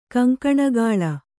♪ kaŋkaṇagāḷa